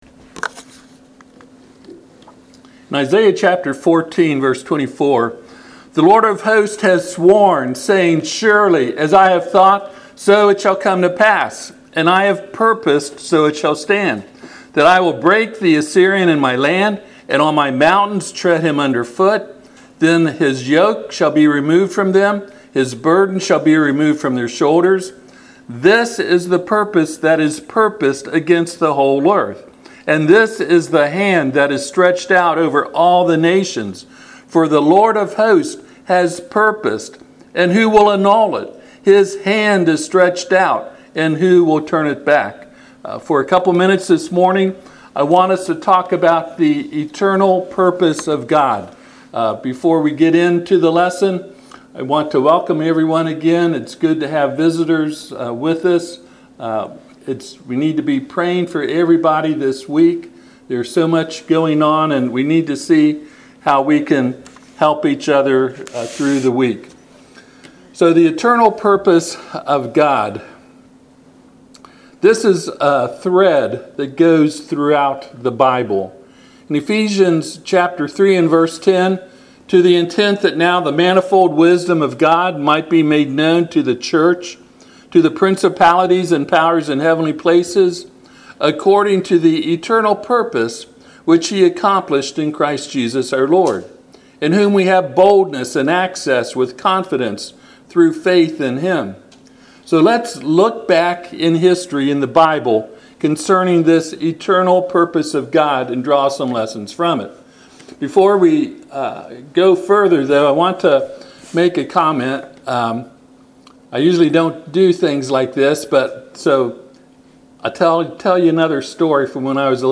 Passage: Ephesians 3:10-11 Service Type: Sunday AM